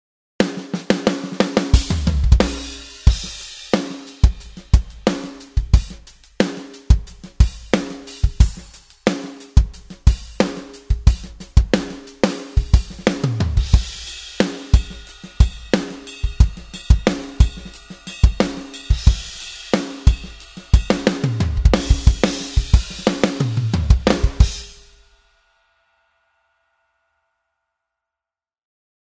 Dynamic, versatile, mix-ready drums
• 10 snare options with massive organic room ambiance
Organic room tones and massive snares
05_Default_Rock.mp3